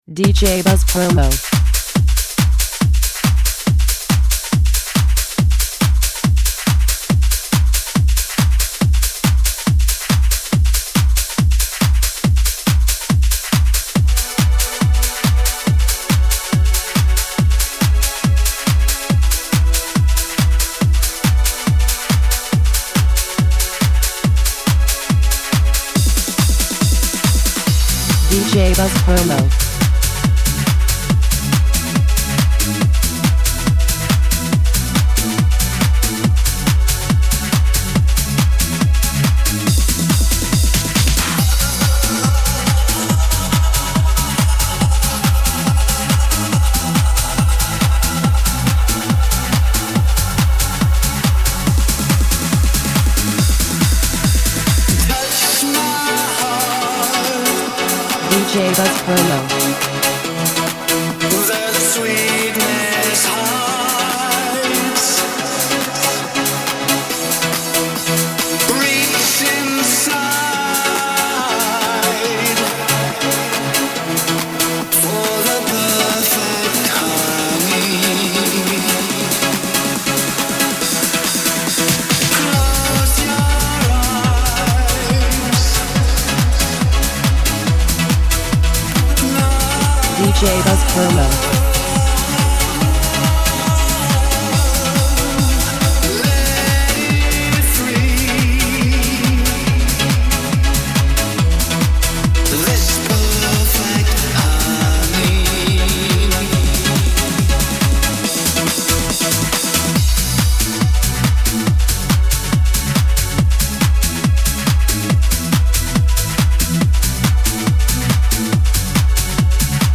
2025 Rework Extended Mix